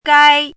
chinese-voice - 汉字语音库
gai1.mp3